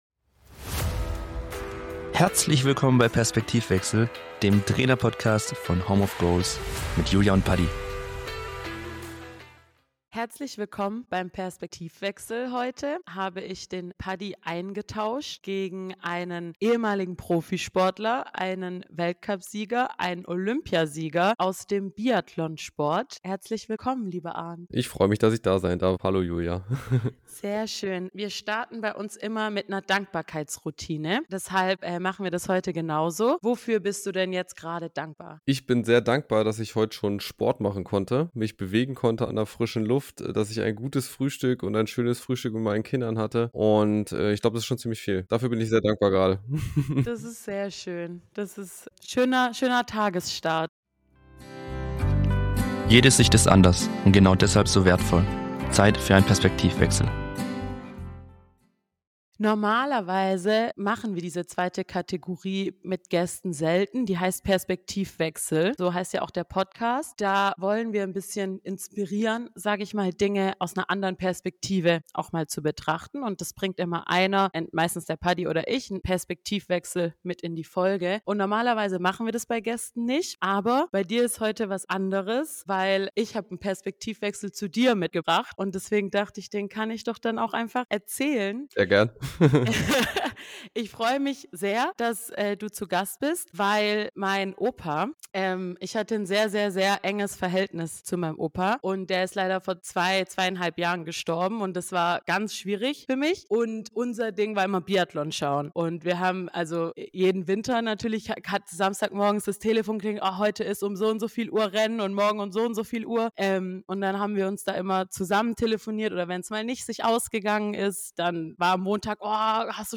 #13 Im Gespräch mit: Arnd Peiffer ~ Perspektivwechsel Mensch.